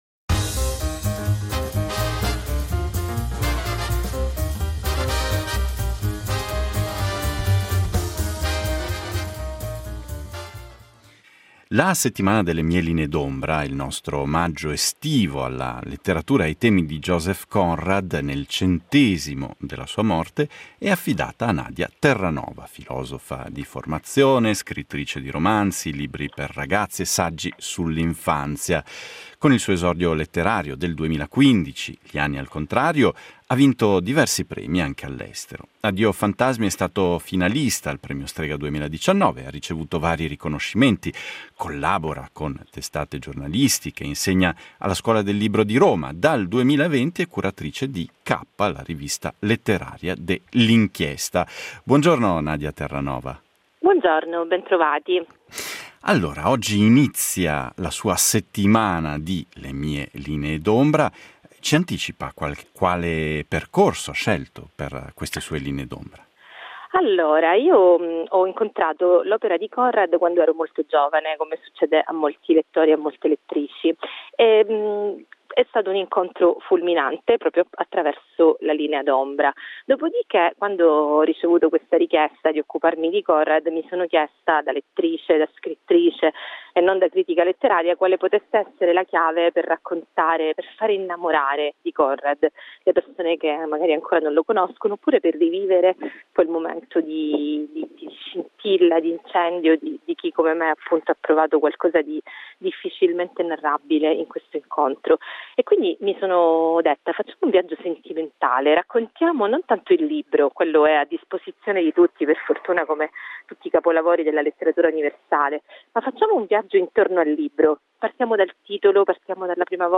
Intervista alla scrittrice italiana